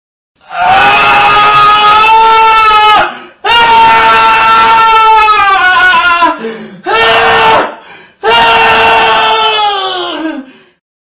Man screaming - MP3 Download
Man screaming sound effect download for free mp3 soundboard online meme instant buttons online download for free mp3